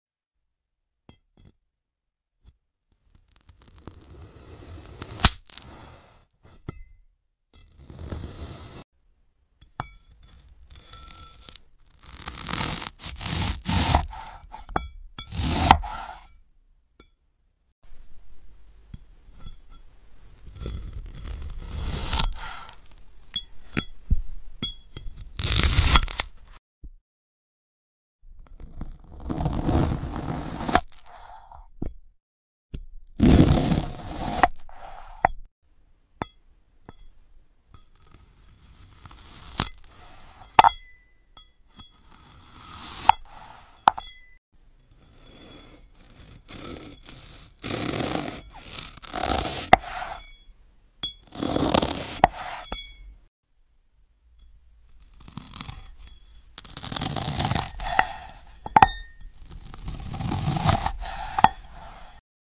Apple Cutting into Slice sound effects free download